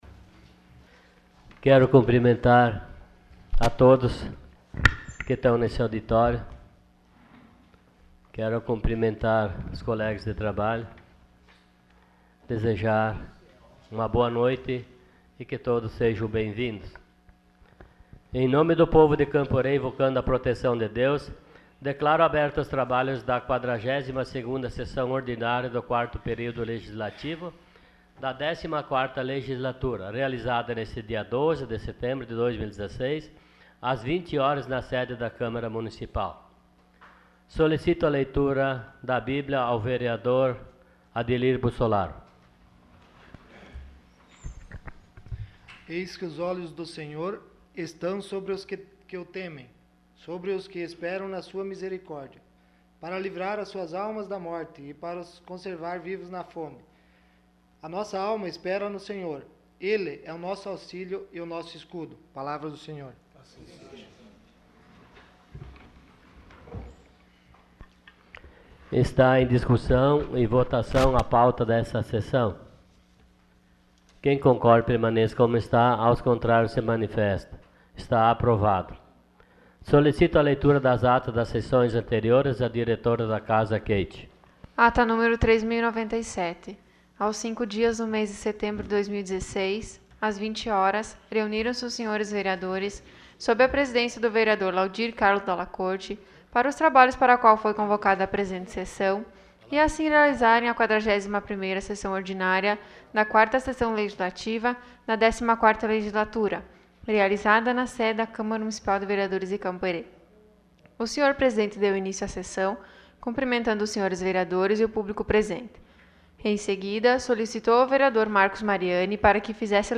Sessão Ordinária dia 12 de setembro de 2016.